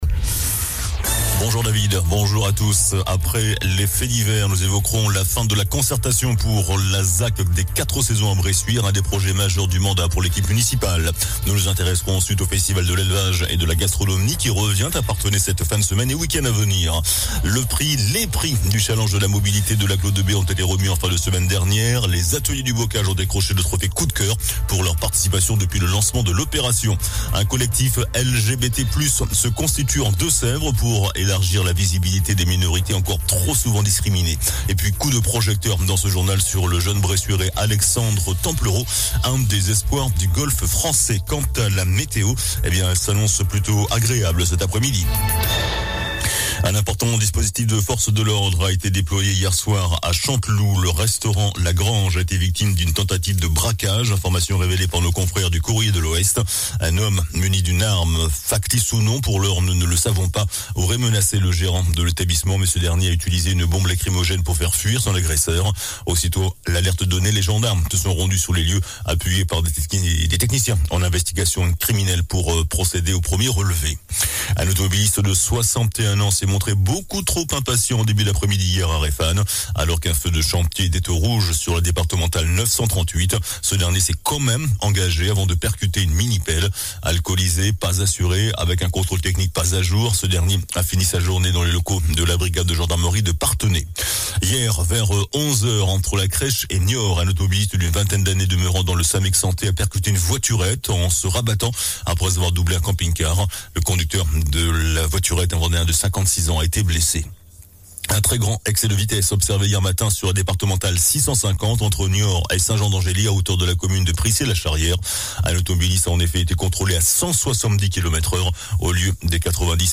JOURNAL DU MARDI 19 SEPTEMBRE ( MIDI )